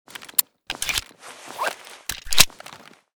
fnp45_reload.ogg